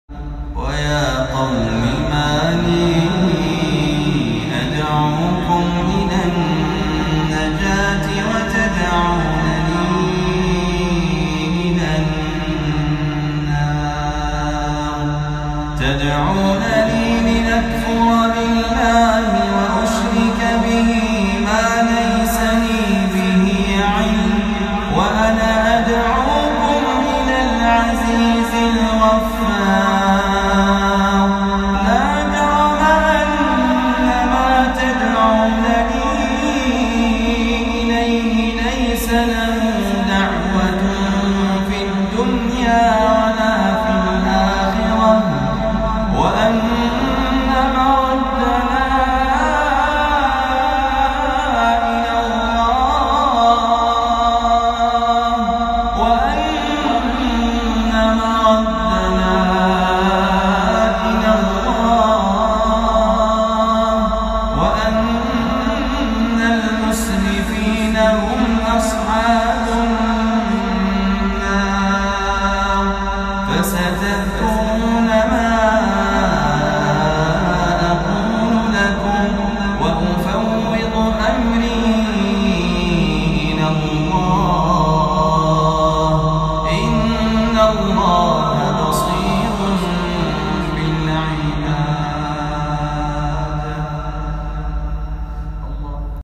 تلاوة خاشعة من سورة غافر